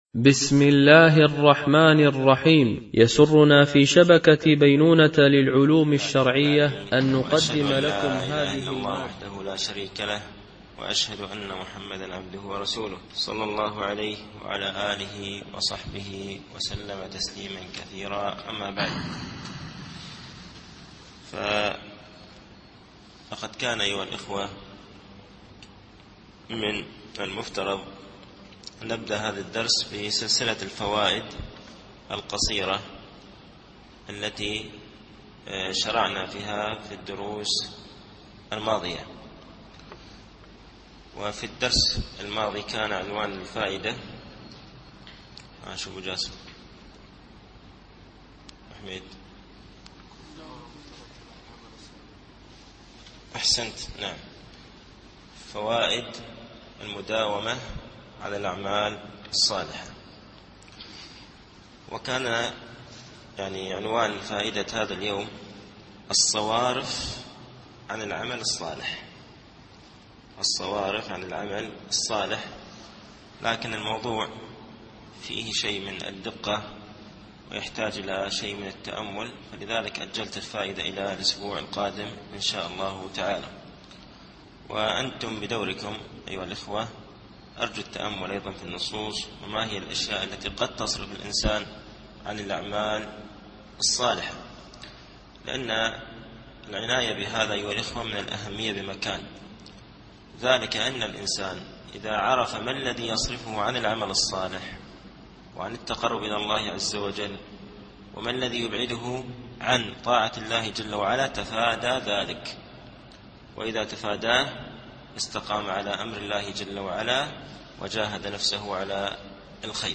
التعليق على كتاب معالم في طريق طلب العلم (وصية23يا طالب العلم تفقد مكتبتك الخاصة) - الدرس السابع و الخمسون